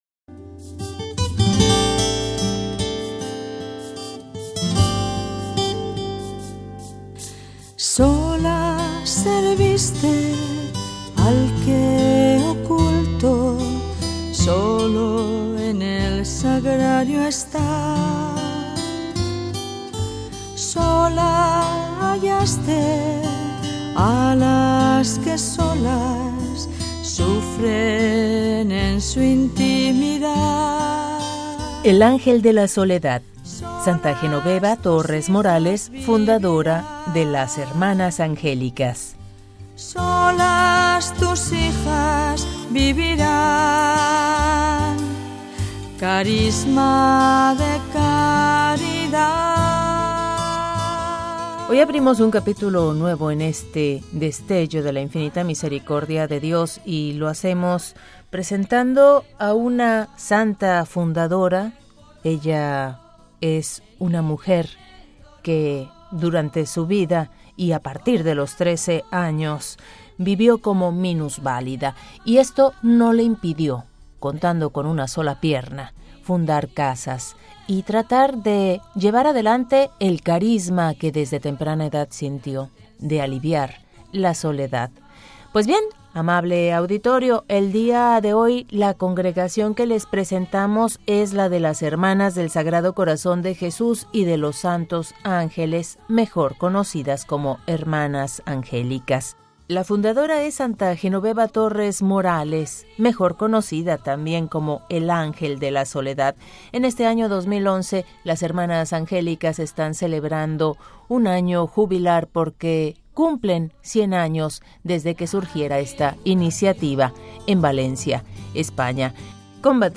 Coros de las Madres Angélicas.